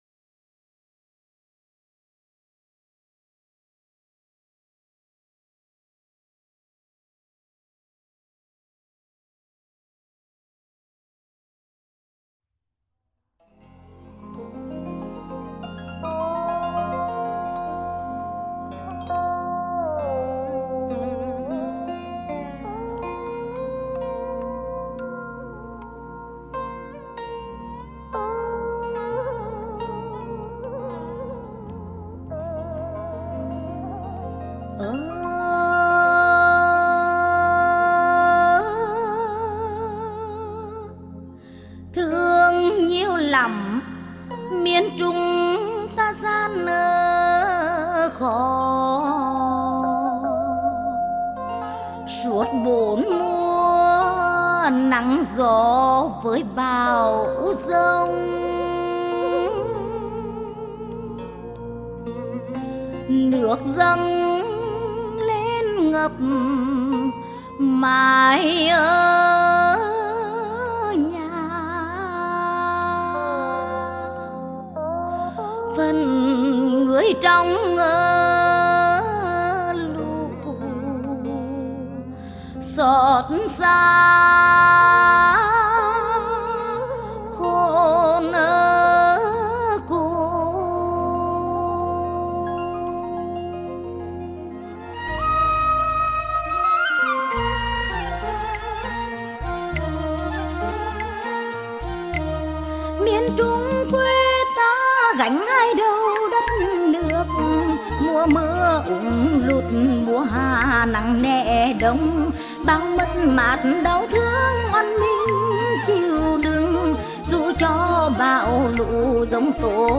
Thể loại: Dân ca Nghệ - Tĩnh, làn điệu Ví và Khuyên